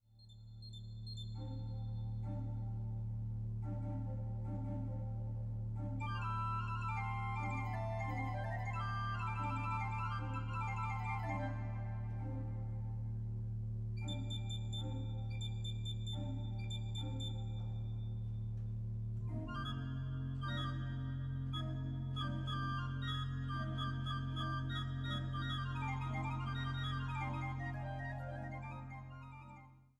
Orgelimprovisationen im Jazzgewand